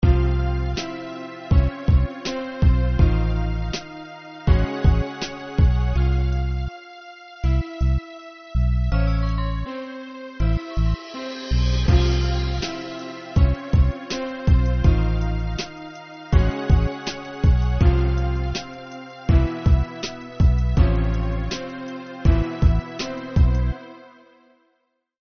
Минусовки (Hip-Hop)
Агрессивные:
5. (инструментальный); темп (98); продолжительность (3:55)